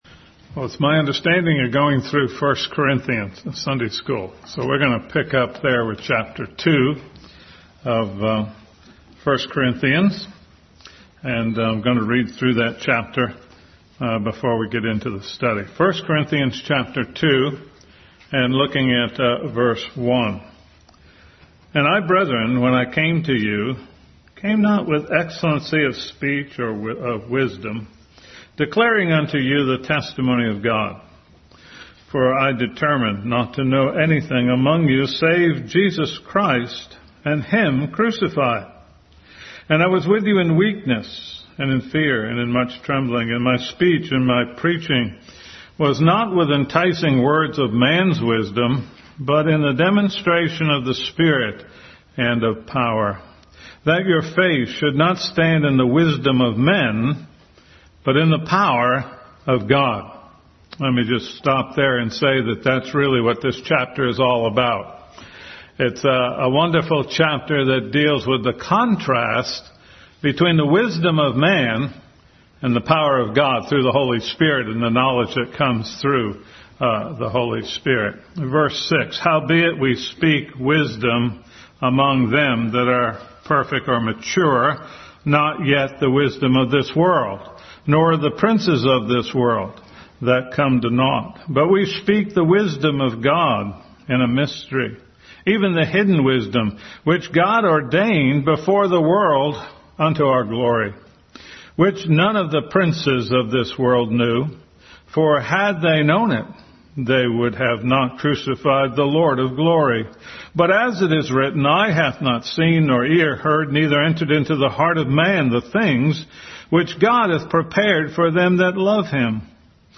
Bible Text: 1 Corinthians 2:1-16, James 3:13-18, Galatians 3:16, Philippians 2:5 | Adult Sunday School continued study in 1 Corinthians.